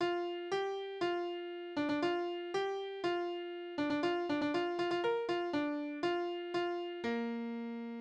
Abzählverse: Zahlen
Tonart: B-Dur
Taktart: 4/4
Tonumfang: Oktave
Besetzung: vokal